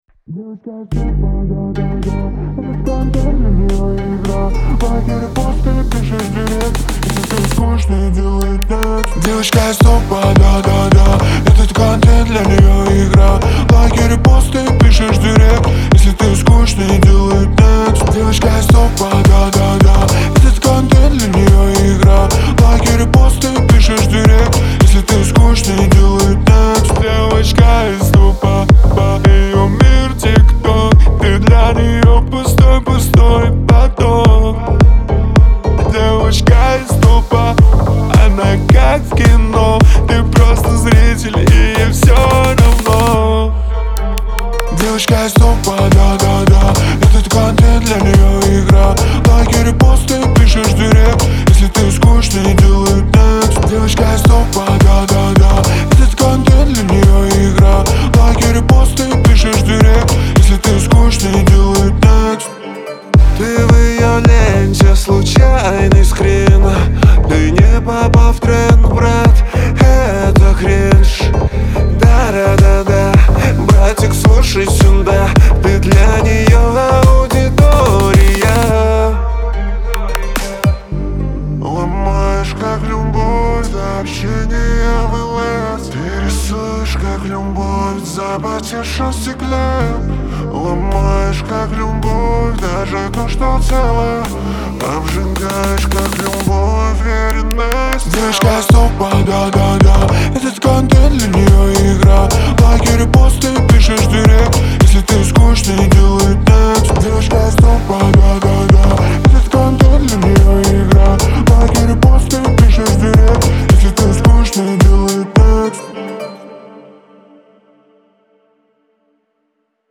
эстрада, dance, ансамбль, Лирика, pop